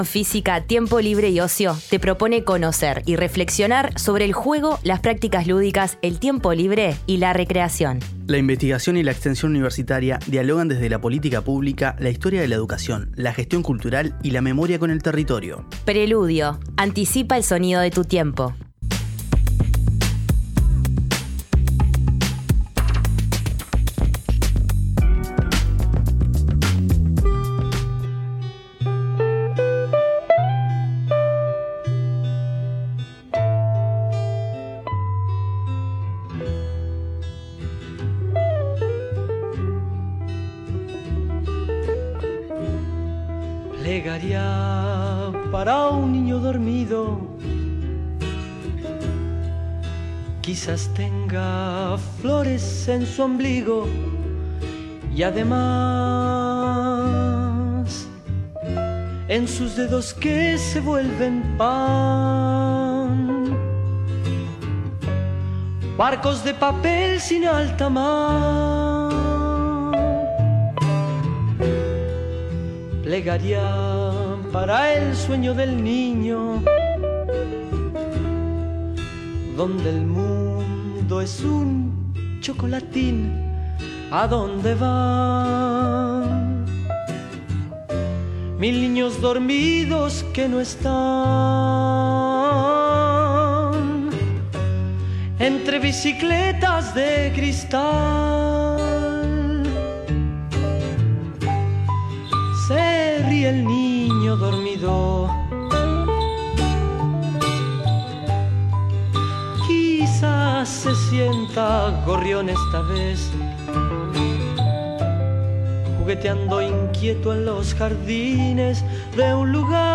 A 50 años del Golpe de Estado, conversaremos sobre el juego y su vínculo con la memoria. En esta ocasión, nos visitan integrantes del colectivo «Memorias de Malvín Norte». Este colectivo trabaja para contar historias sobre aquellos años y construir la memoria sobre el barrio.